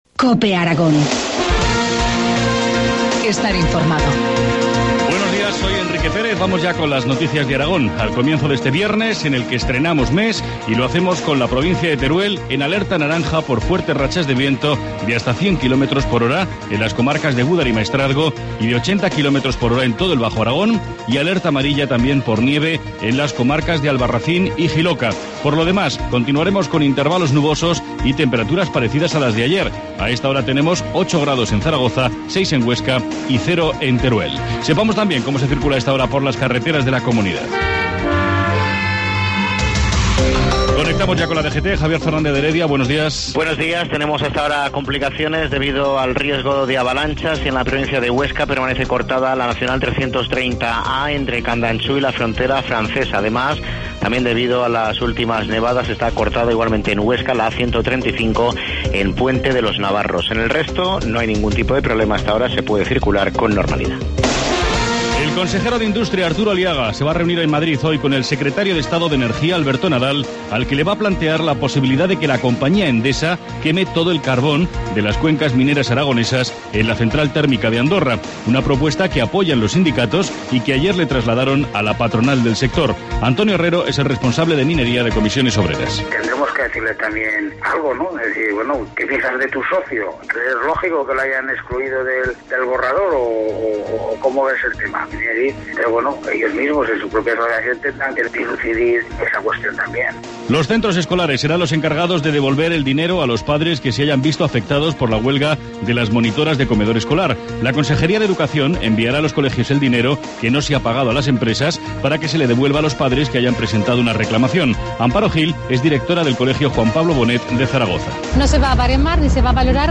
Informativo matinal, viernes 1 de febrero, 7.25 horas